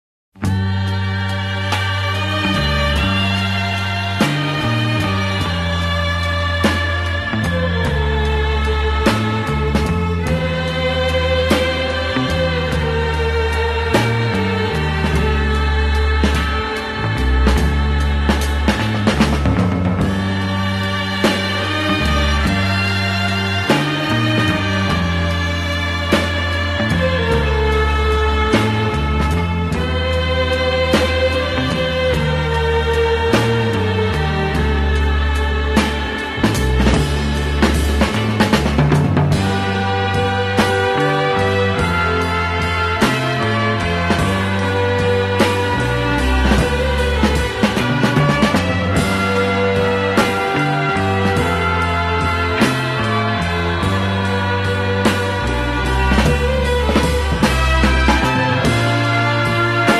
Step into a surreal ASMR sound effects free download
From squishy bubble wrap to soft noodles and solid rock floors, every step and sit is a sensory adventure.